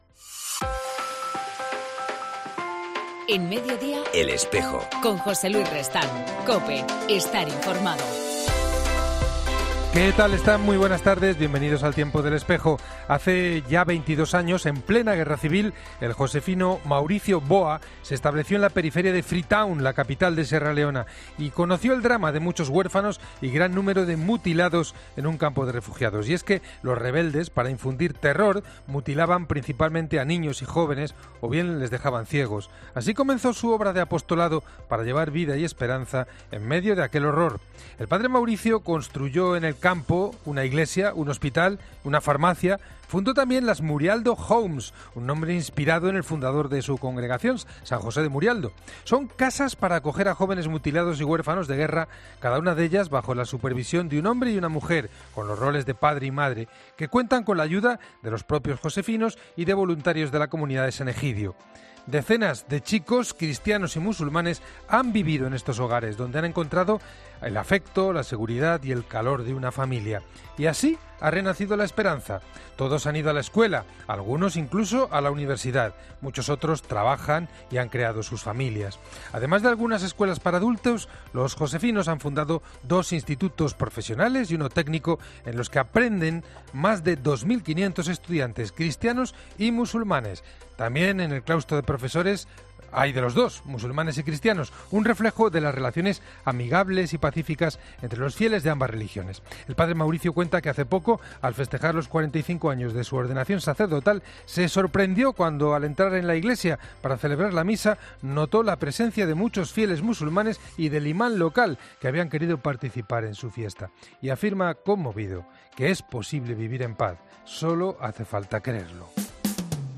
En El Espejo del 24 de abril entrevistamos a Fidel Herráez, Obispo de la Archidiócesis de Burgos
Ya está en marcha un amplio programa de actividades que jalonarán el recorrido de estos tres años. Para contárnoslo nos acompaña el Arzobispo de Burgos, Mons. Fidel Herráez.